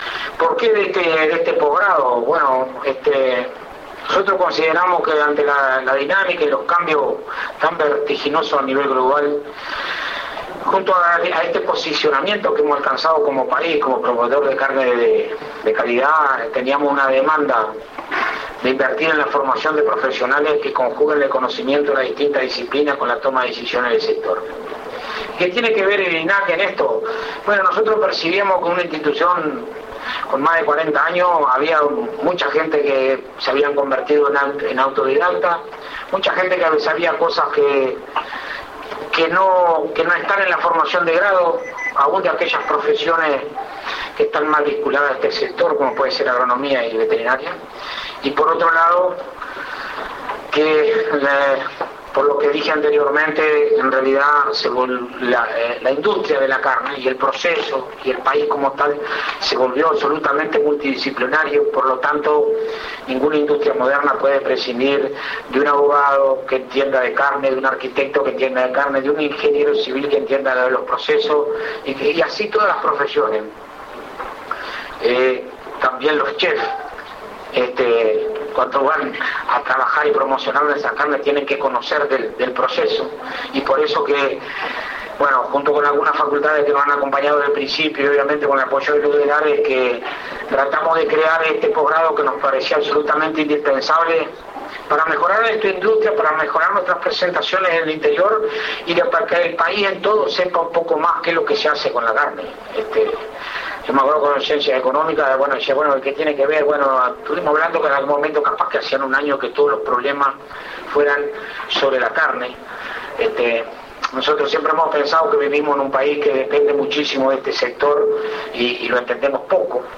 La actividad de cierre de la 3° Edición contó con la presencia del Ministro de Industria Roberto Kreimerman, del Rector de la Universidad de la República Roberto Markarian , el Subsecretario de Ganadería Enzo Benech , El Presidente y Vicepresidente de INAC Alfredo Fratti y Fernando Pérez Abella.
El Presidente de INAC Dr. Alfredo Fratti manifestó que la industria cárnica moderna necesita de todas las profesiones.